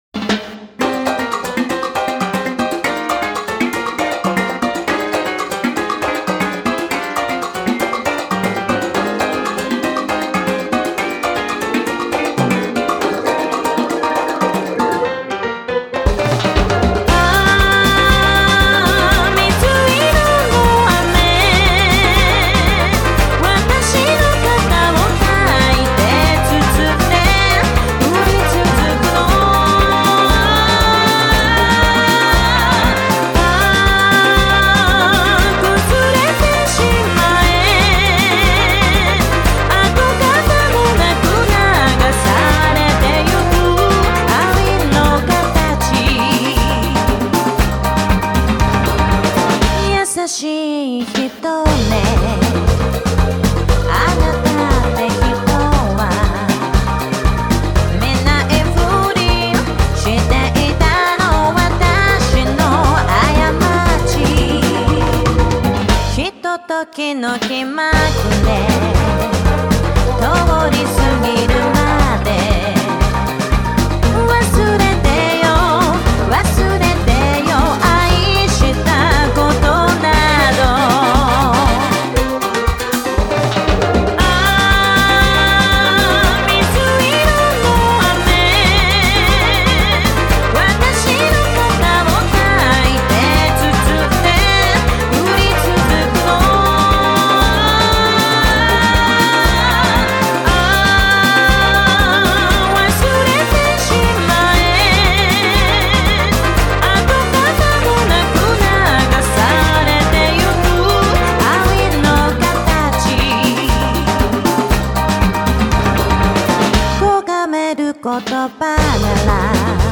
ジャンル(スタイル) JAPANESE POP / LATIN / DISCO